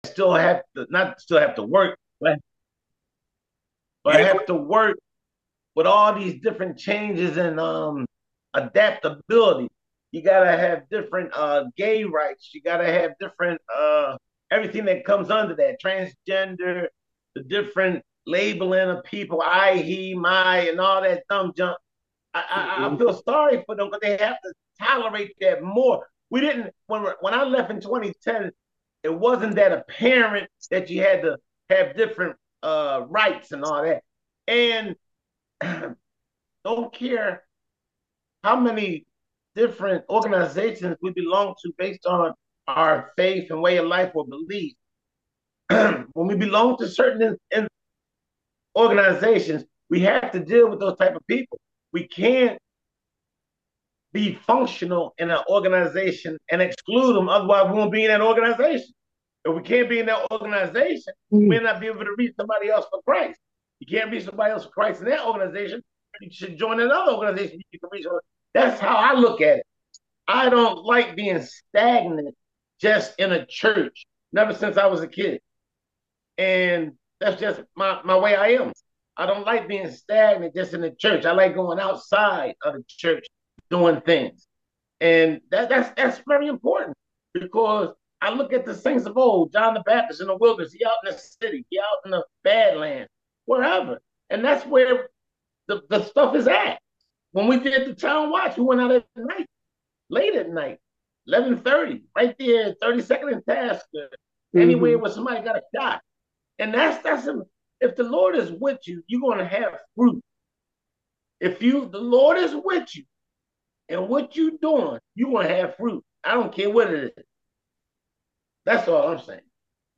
St James Bible Study – LYING